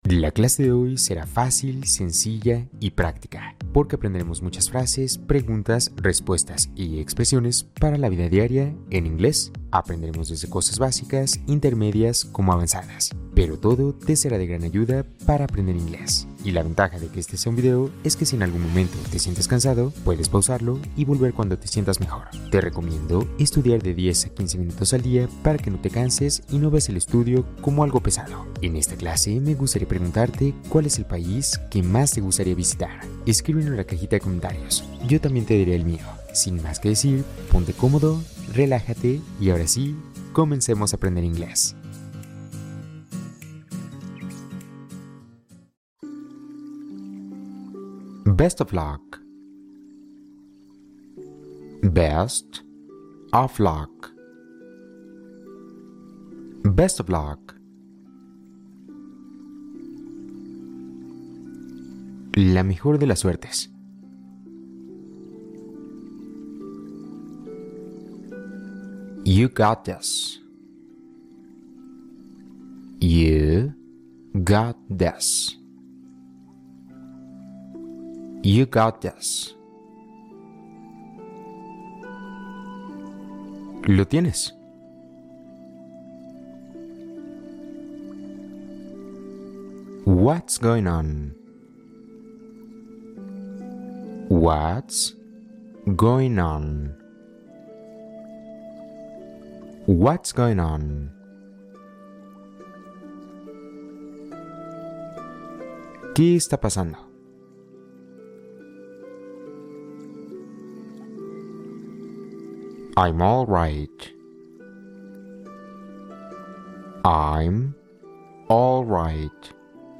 La vida diaria en inglés explicada con listening lento